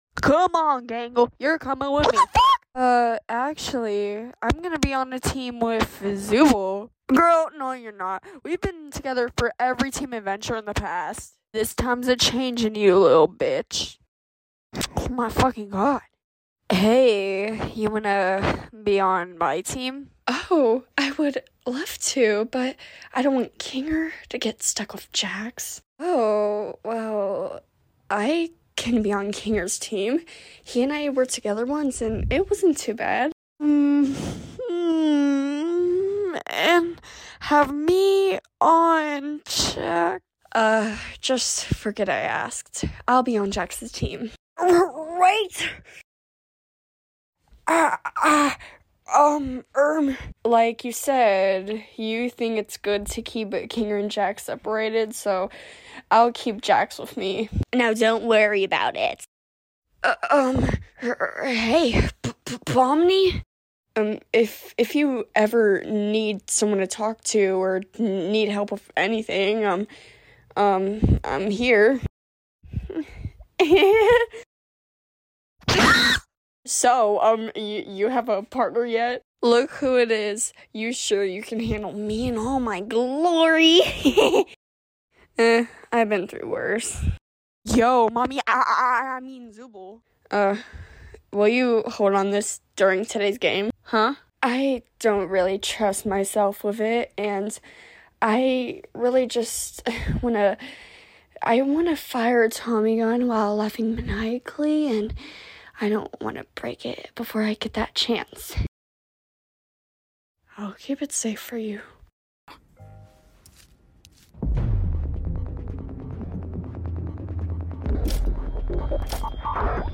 It’s long as fuck burps sound effects free download